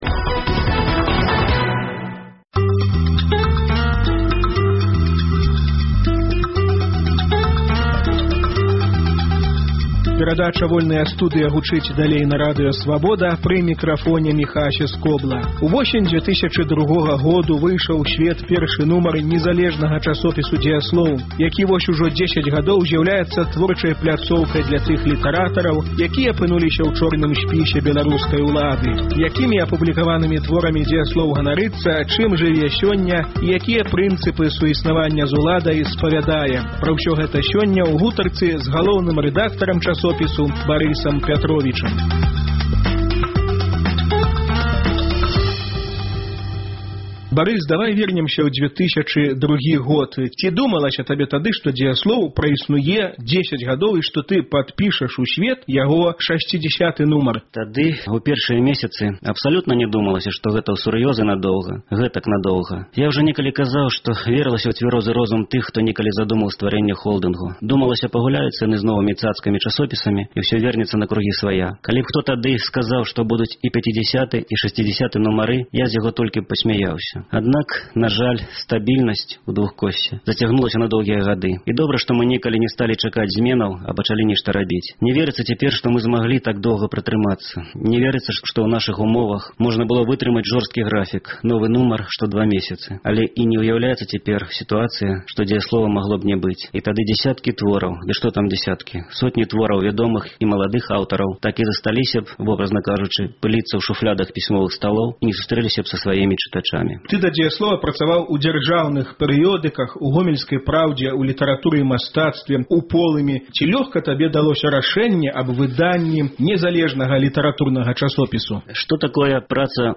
пра ўсё гэта ў гутарцы